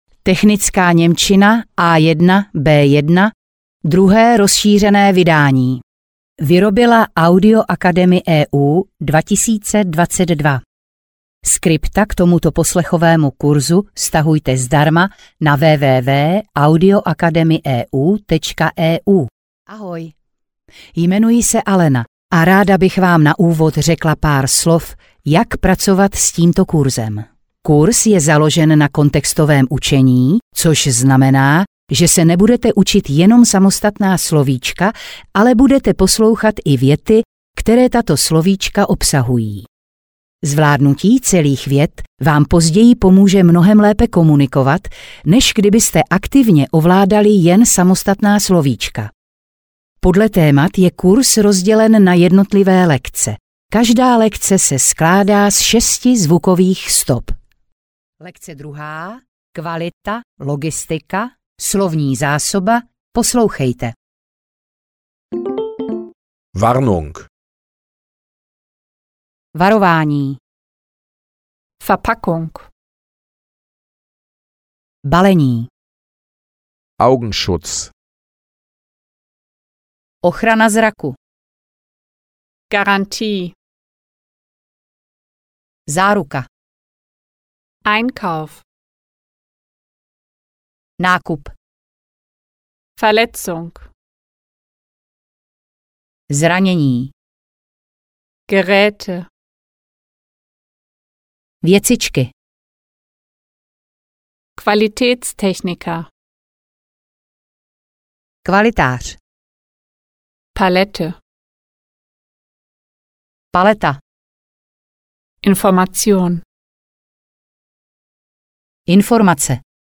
Technická němčina A1-B1 audiokniha
Ukázka z knihy